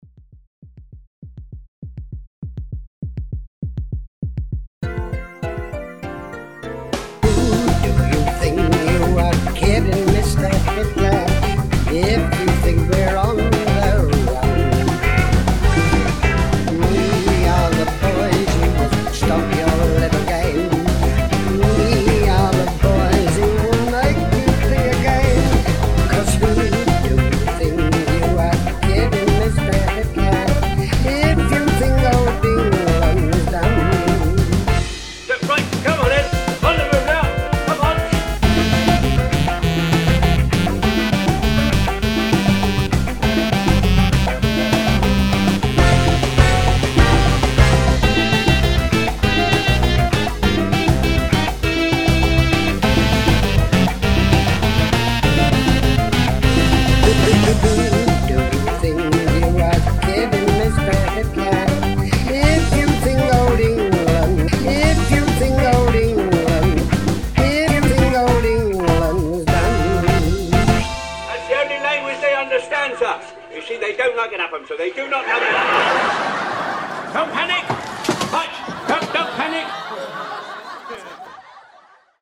Mambo version